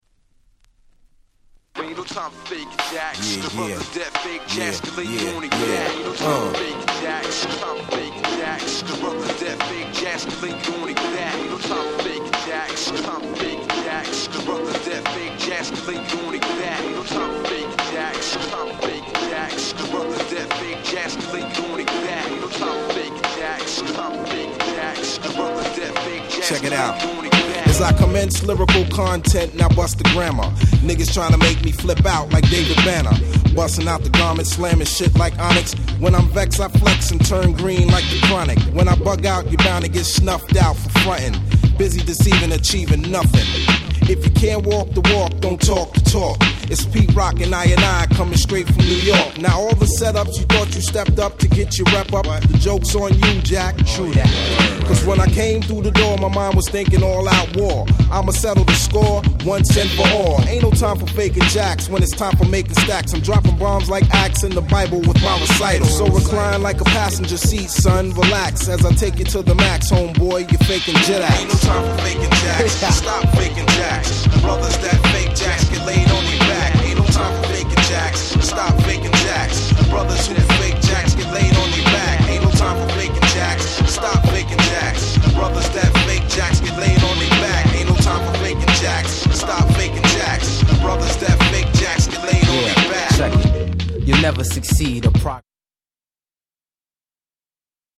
ピートロック Boom Bap 90’s ブーンバップ